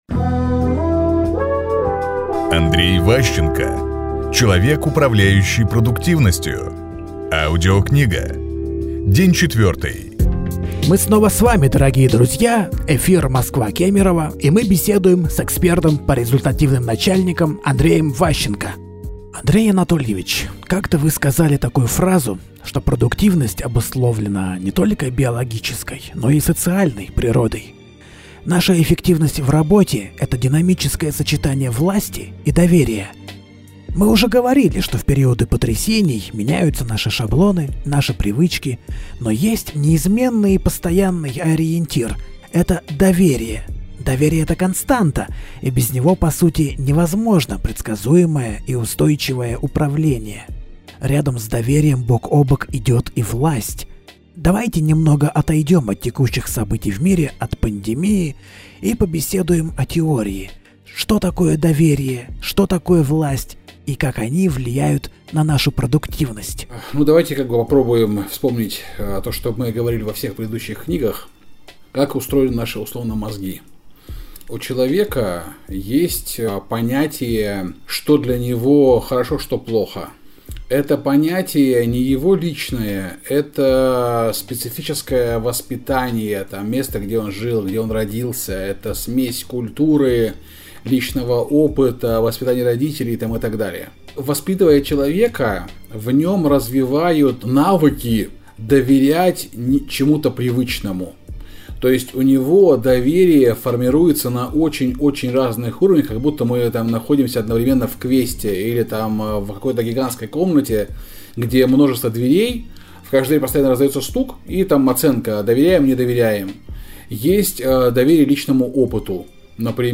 Аудиокнига Человек, управляющий продуктивностью. Часть 4 | Библиотека аудиокниг